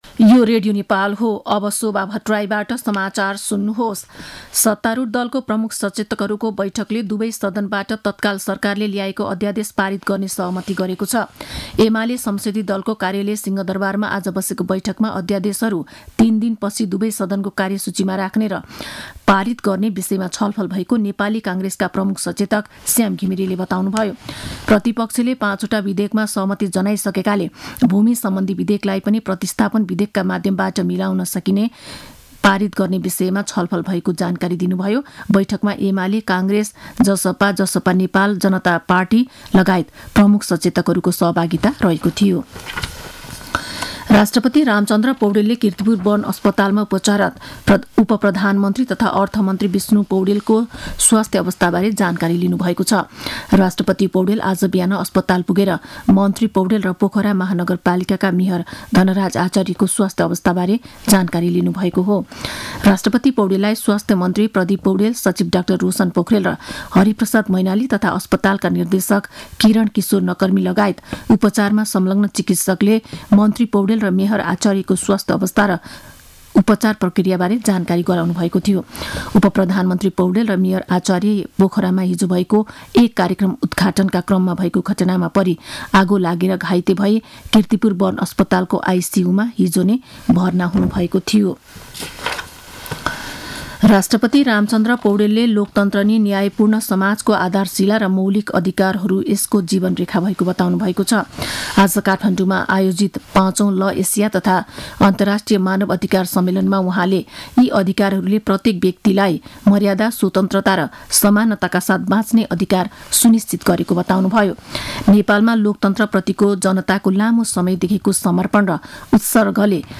दिउँसो १ बजेको नेपाली समाचार : ५ फागुन , २०८१